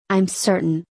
（アイム　ポジティブ）